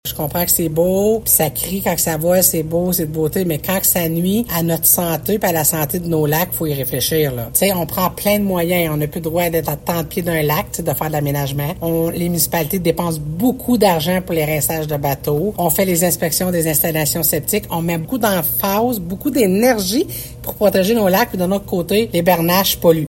Toutefois, la préfète précise que la MRC ne se lance pas dans un plan d’extermination de cet animal qui demeure magnifique. L’objectif est que la démographie des bernaches soit régulée davantage :